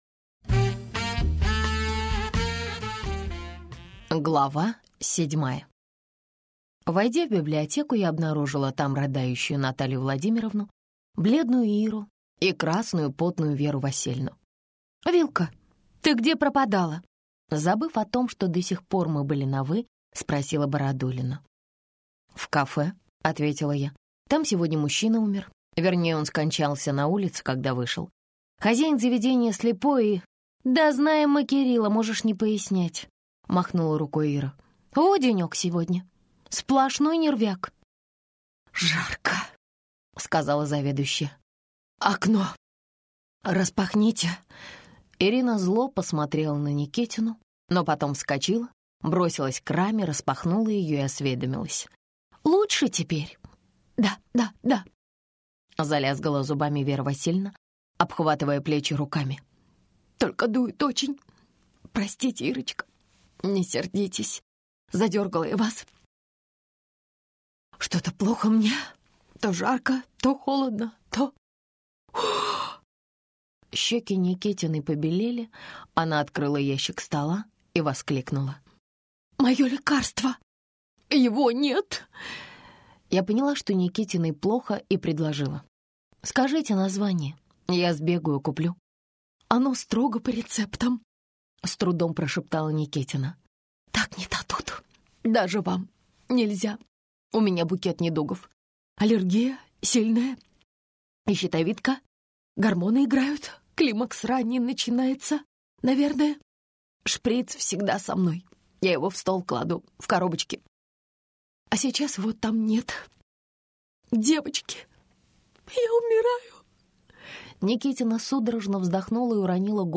Аудиокнига Закон молодильного яблочка - купить, скачать и слушать онлайн | КнигоПоиск